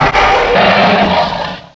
Cri de Trioxhydre dans Pokémon Noir et Blanc.